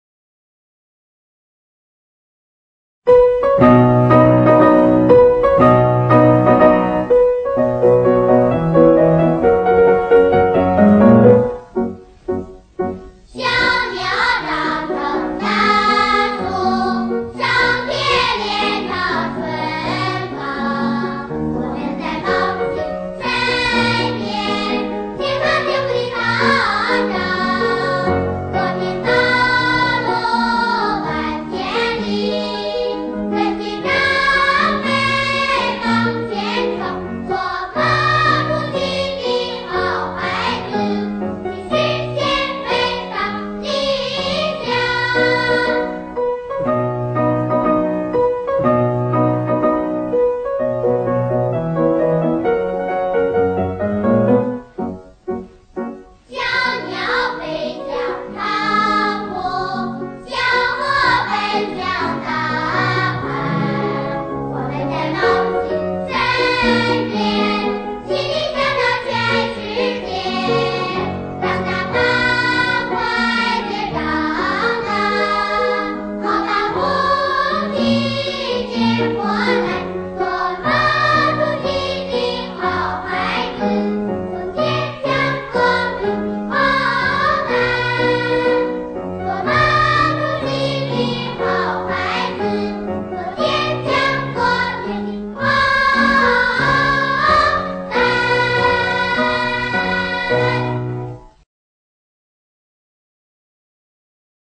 (童声齐唱)